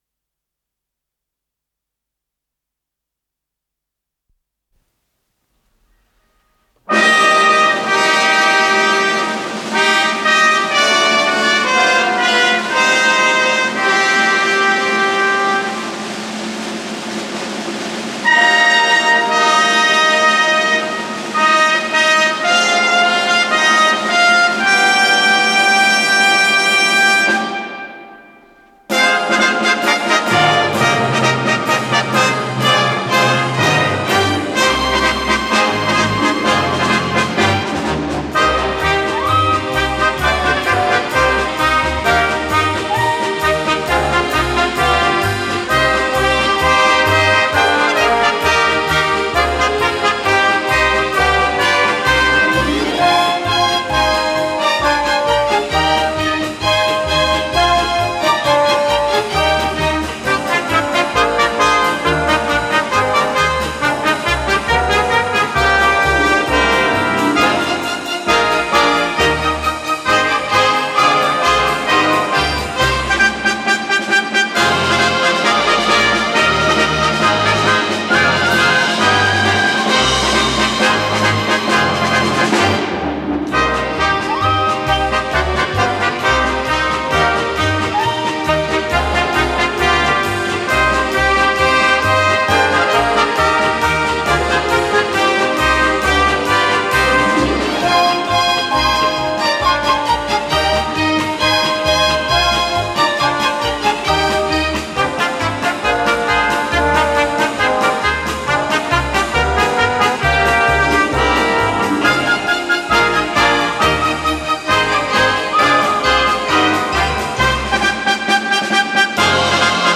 с профессиональной магнитной ленты
ПодзаголовокЗаставка, до минор
ВариантДубль моно